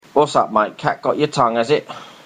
Examples of Cockney English
//wɒssæ̙p/mʌɪʔ/kæʔ gɒʔ jə tæ̙ŋ/æz ɪʔ//
Notice that there is no /t/ in what's -this is typical of Cockney.
09_Cockney_Whats_up_mate_cat_got_your_tongue.mp3